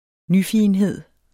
Udtale [ ˈnyˌfiˀənˌheðˀ ]